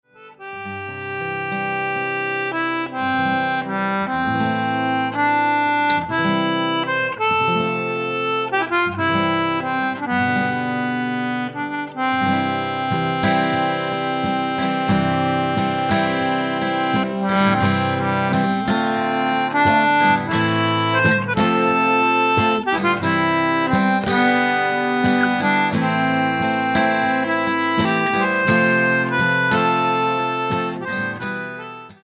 - slow Newfoundland instrumental played on the concertina